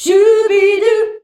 SCHUBIDU A.wav